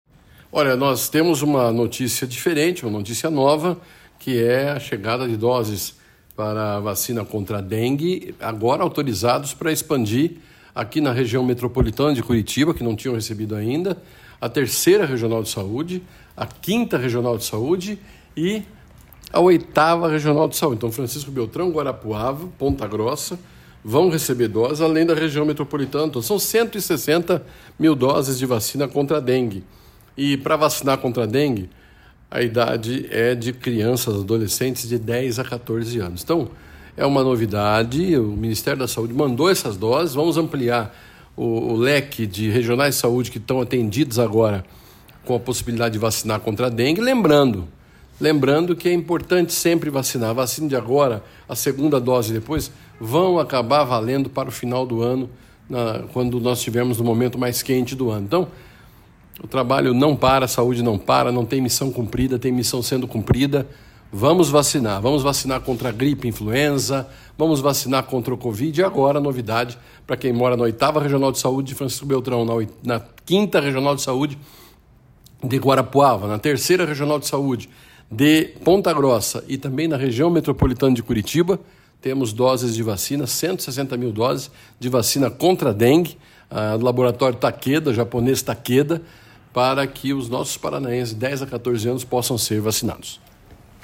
Sonora do secretário Estadual da Saúde, Beto Preto, sobre a ampliação da vacinação contra a dengue para mais 102 municípios